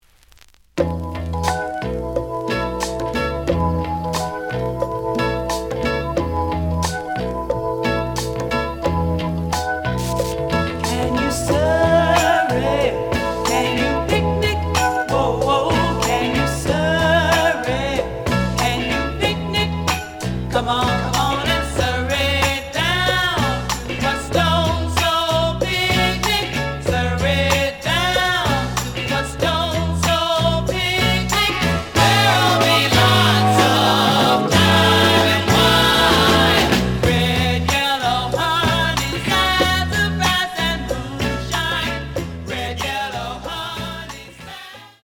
The audio sample is recorded from the actual item.
●Genre: Soul, 60's Soul
Some noise on beginning of A side, but almost good.)